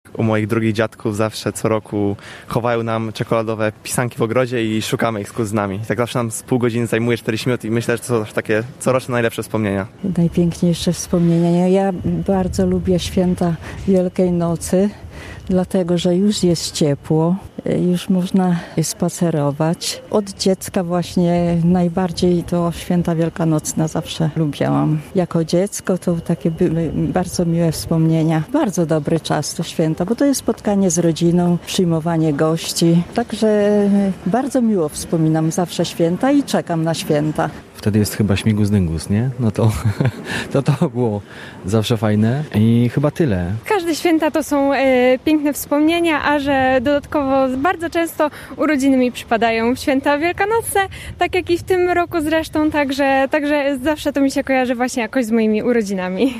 Najpiękniejsze wspomnienia wielkanocne (sonda)
Sprawdziliśmy jakie są najpiękniejsze wspomnienia mieszkańców Rzeszowa związane z Wielkanocą?